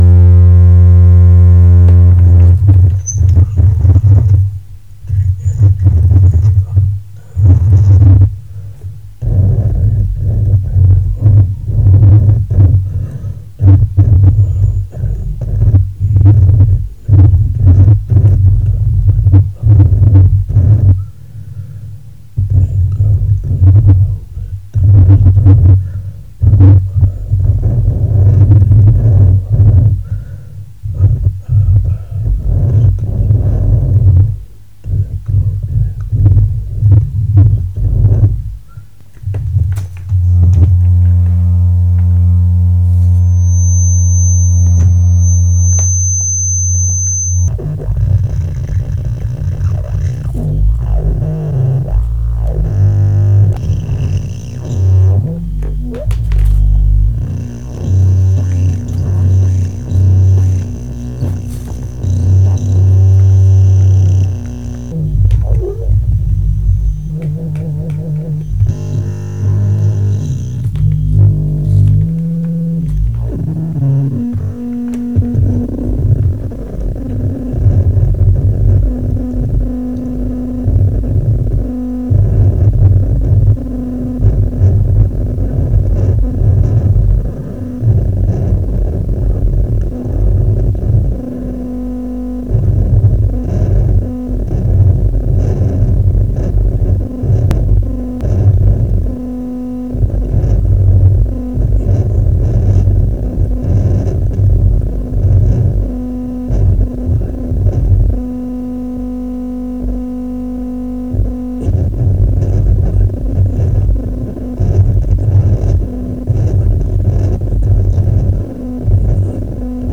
thumper range recording mono 24 sep 2015